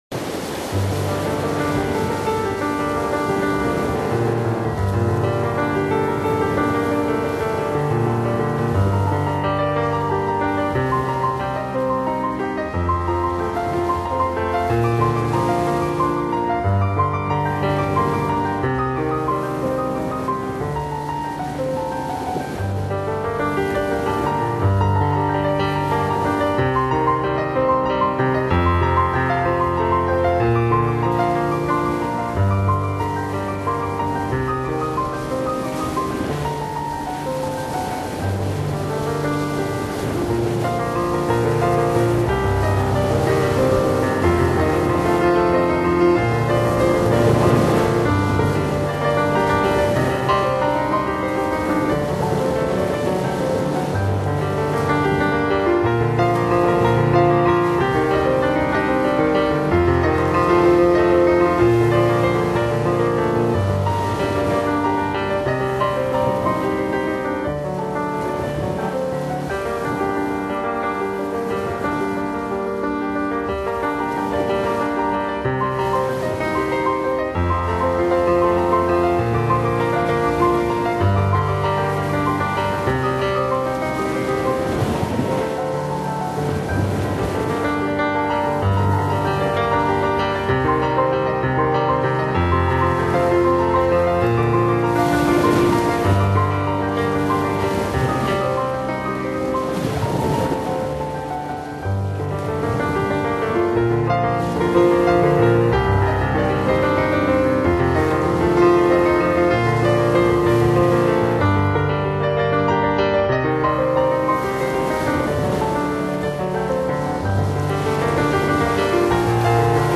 她的钢琴独奏犹如与乐队齐奏，交响效果浑厚。
乐曲的旋律和节奏热烈奔放，势如熊熊火焰，情同奔向太阳，真挚狂热